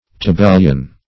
Search Result for " tabellion" : The Collaborative International Dictionary of English v.0.48: Tabellion \Ta*bel"lion\, n. [L. tabellio, fr. tabella a tablet, a writing, document, dim. of tabula a board: cf. F. tabellion.